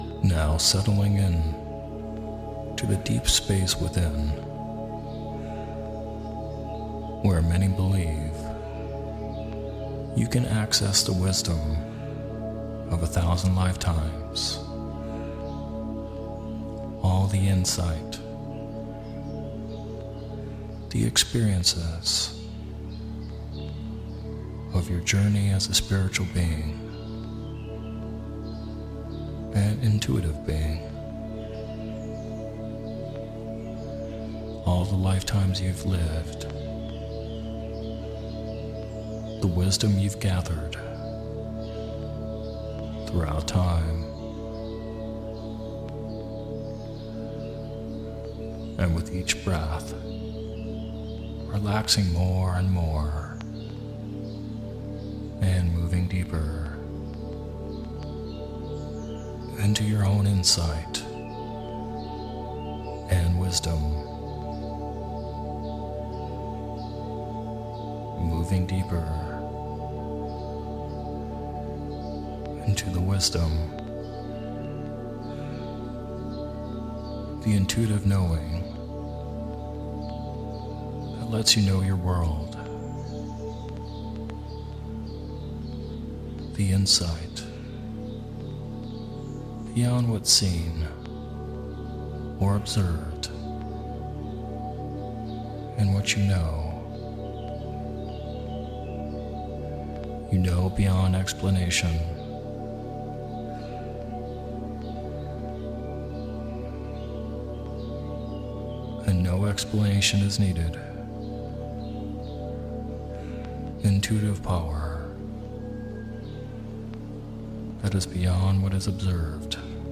Sleep Hypnosis for Connecting to your Intuition
This audio was created in attempt to connect to your intuition or maybe your higher self…whatever resonates with you. This audio is in a lo-fi vintage style so it includes vinyl sounds.